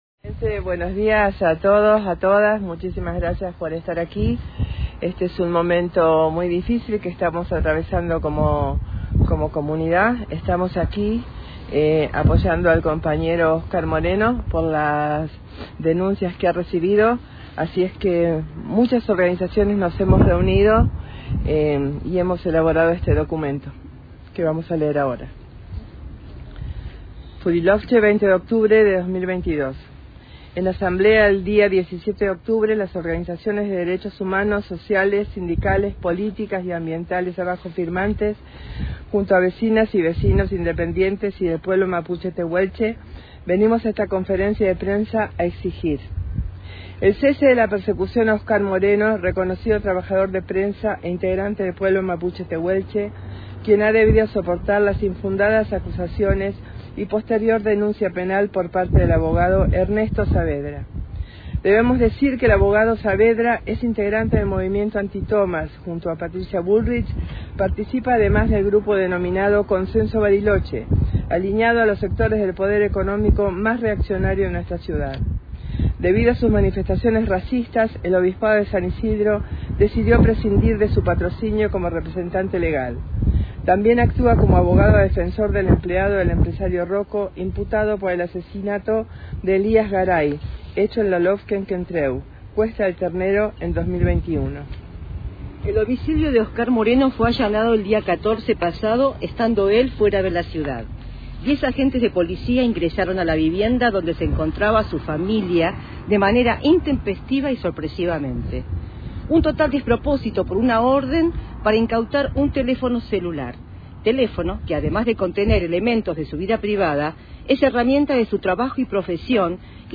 En dicha conferencia, convocada por organizaciones sociales de derechos humanos, políticas, gremiales e independientes, se leyó, un documento colectivo de todas las organizaciones.
1-LECTURA-DE-DOCUMENTO-DE-ORGANIZACIONES.mp3